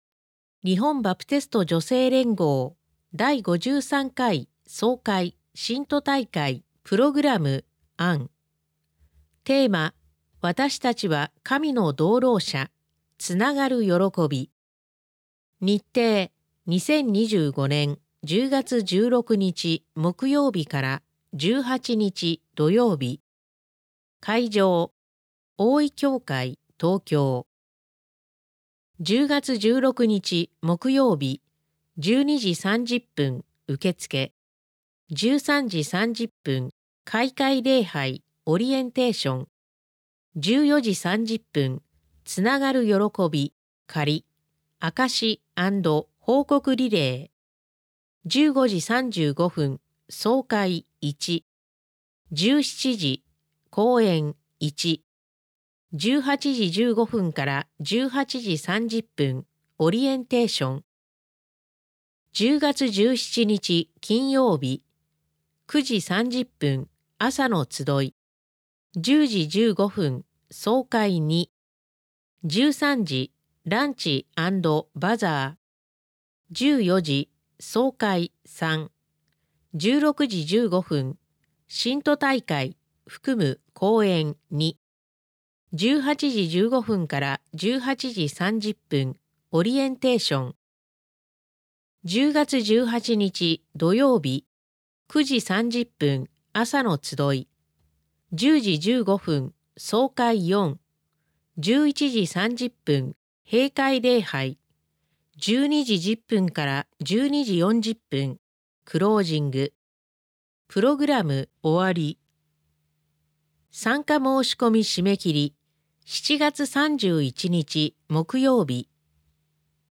2025年４月号から『世の光』Webの各記事から、朗読者による音声（オーディオ）配信がスタートしました。